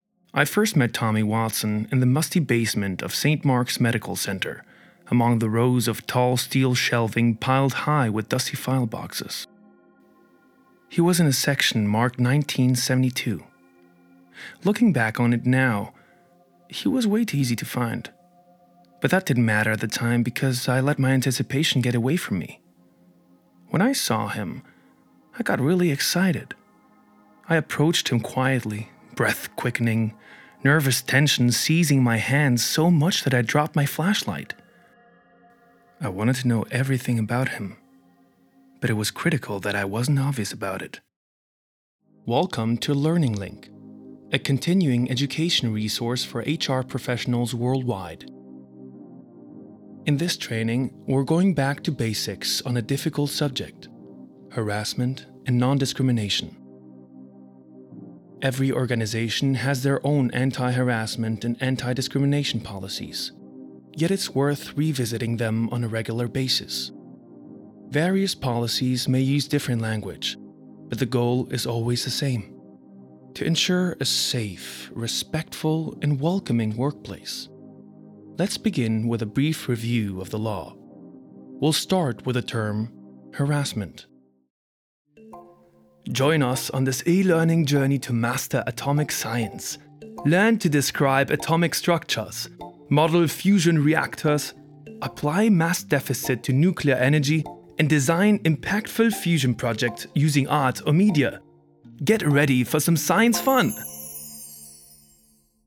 sehr variabel, hell, fein, zart, markant
Mittel minus (25-45)
Narration Reel Englisch
Audiobook (Hörbuch), Audioguide, Tale (Erzählung)